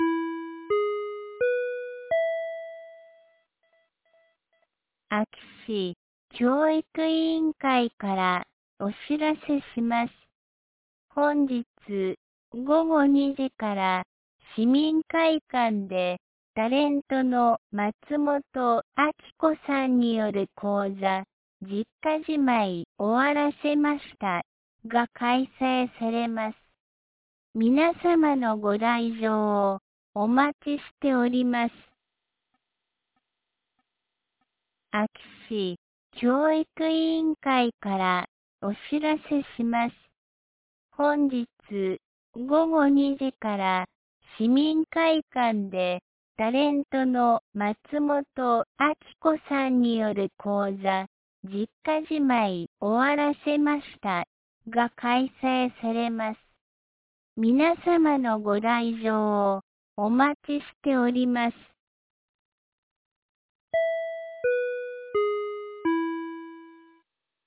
2025年09月06日 12時41分に、安芸市より全地区へ放送がありました。